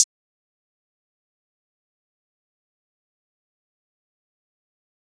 Tapped Hat.wav